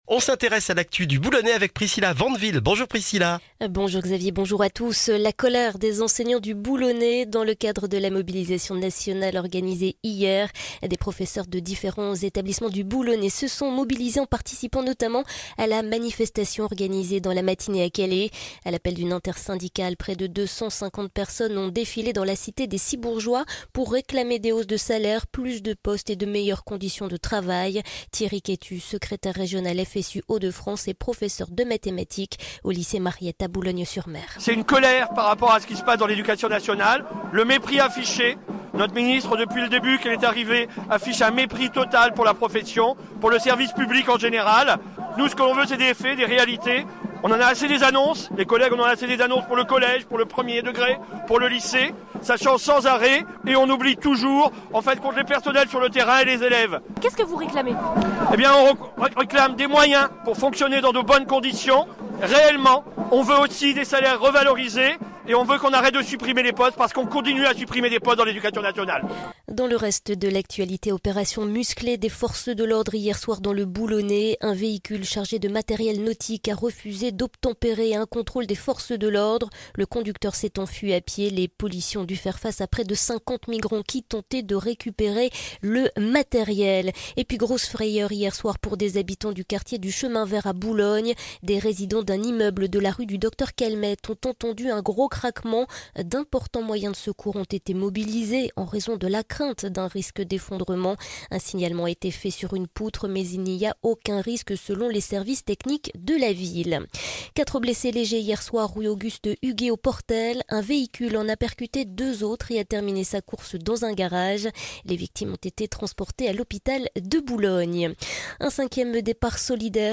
Le journal du vendredi 2 février dans le Boulonnais